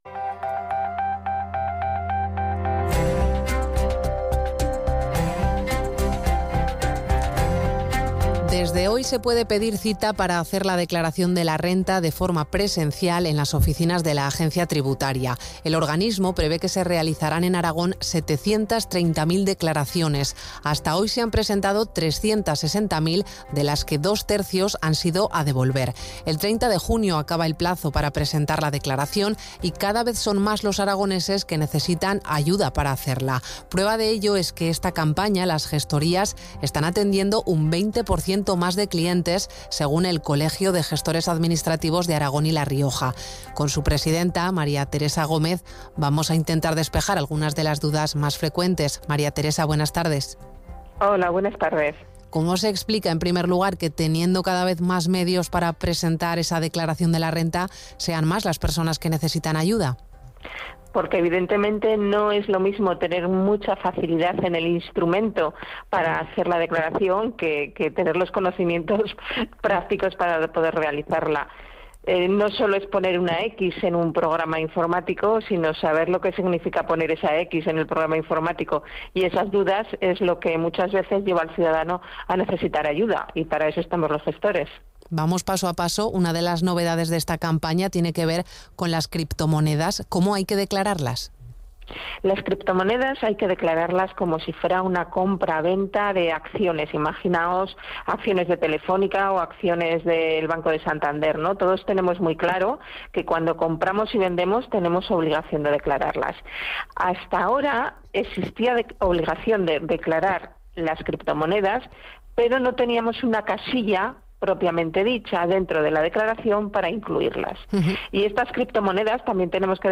Aragón Radio – Entrevista